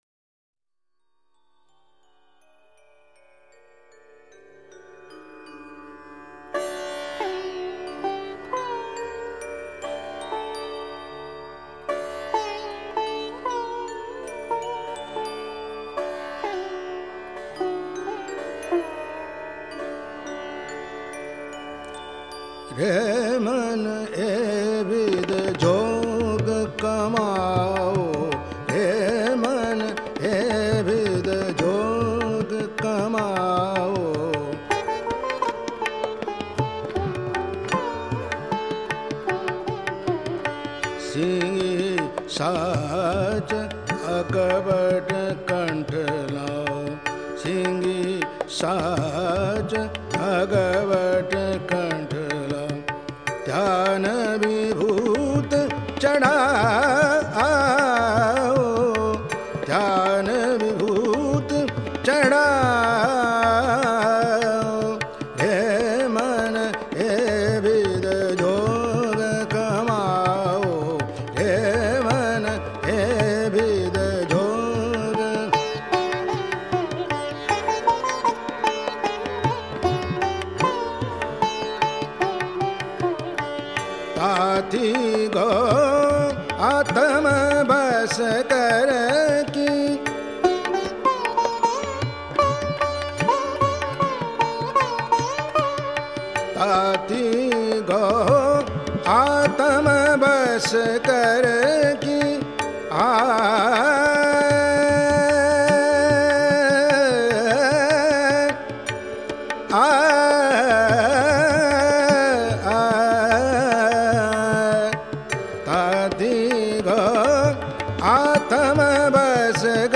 Post Your Favorite Kirtan